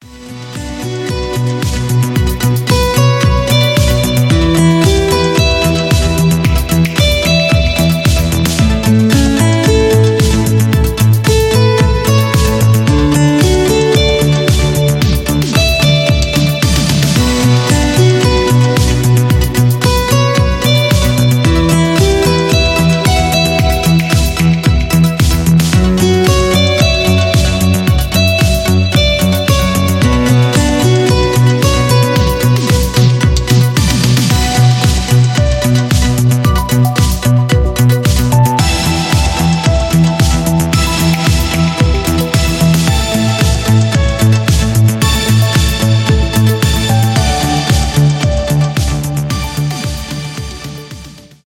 итало диско , без слов
инструментальные , танцевальные , мелодичные